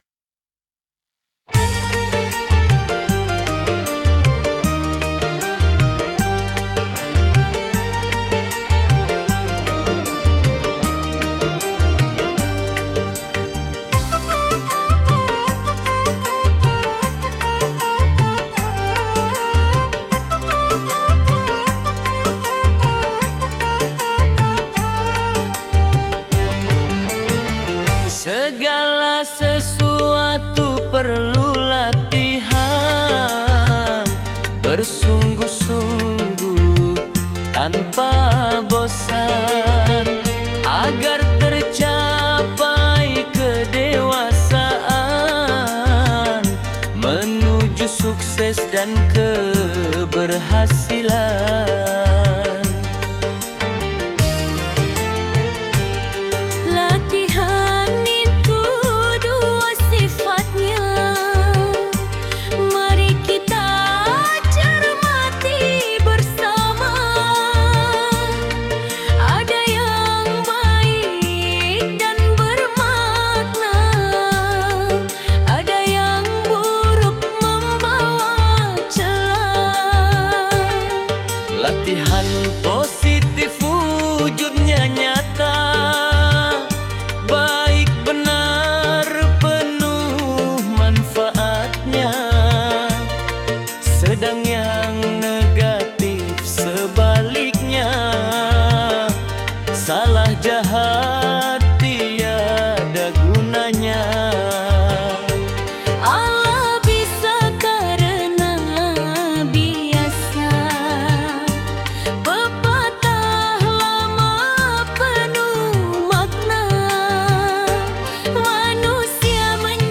Dengarkan musik dan lagunya yang syahdu